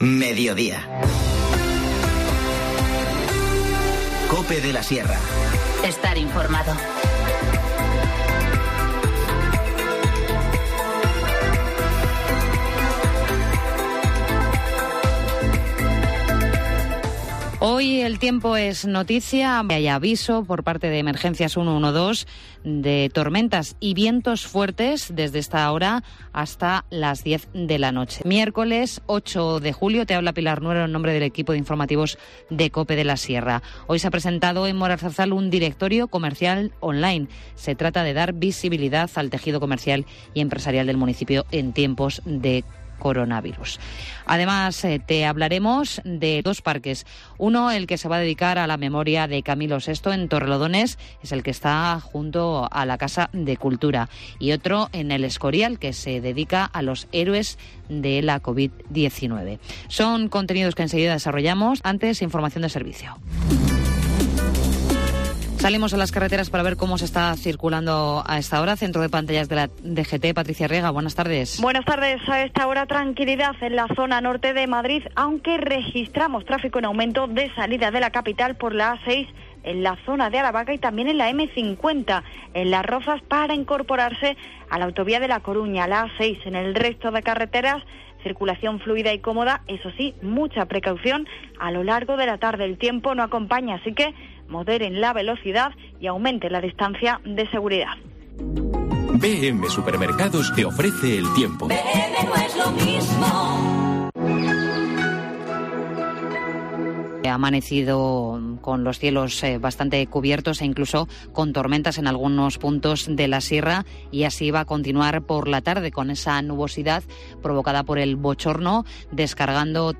Informativo Mediodía 8 julio 14:20h